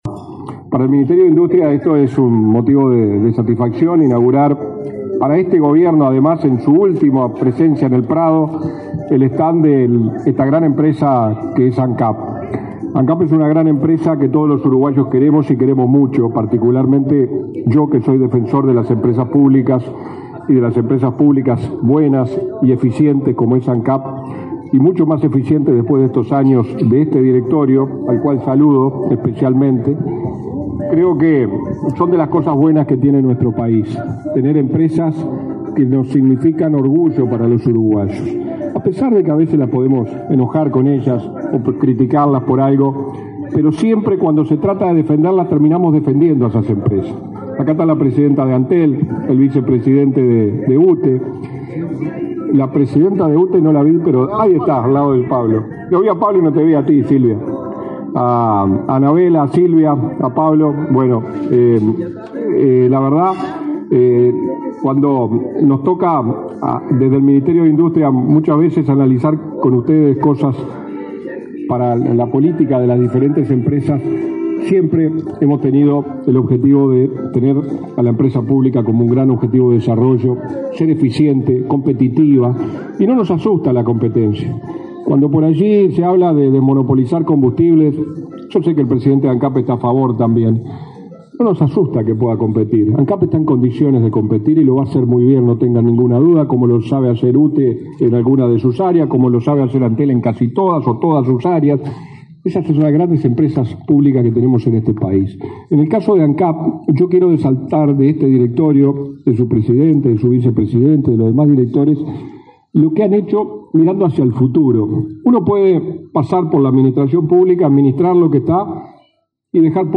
Palabra de autoridades en inauguración de stand de Ancap en la Expo Prado
Palabra de autoridades en inauguración de stand de Ancap en la Expo Prado 06/09/2024 Compartir Facebook X Copiar enlace WhatsApp LinkedIn El ministro interino de Industria, Walter Verri, y el presidente de Ancap, Alejandro Stipanicic, participaron, este viernes 6, en la inauguración del stand del ente petrolero en la Expo Prado.